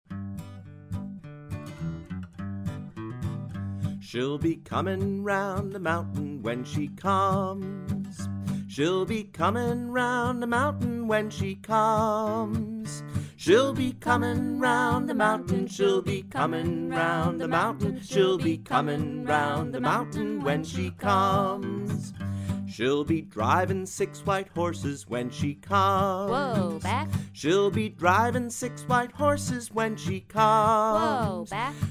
Folk Song